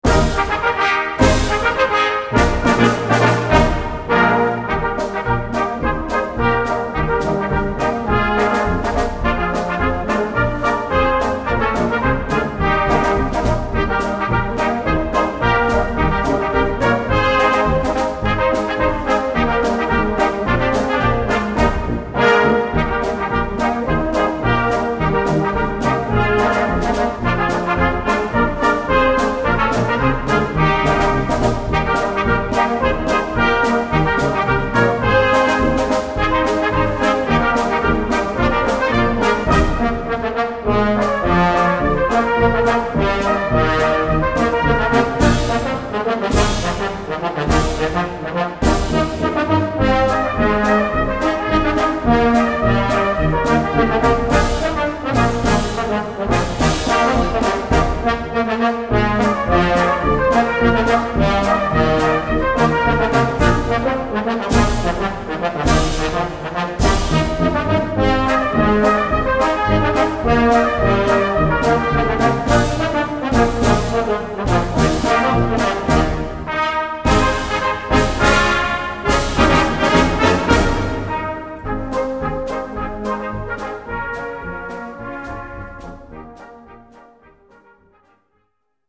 Répertoire pour Brass band - Brass Band